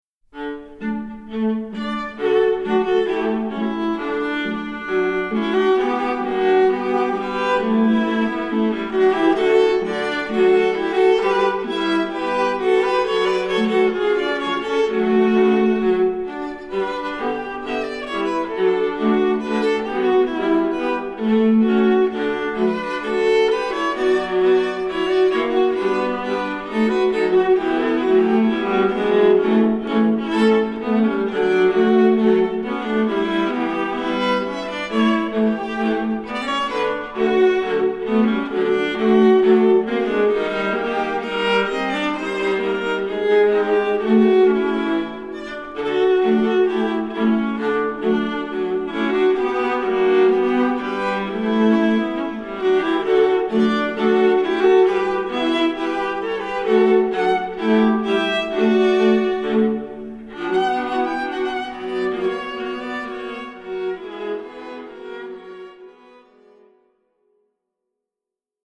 Voicing: Viola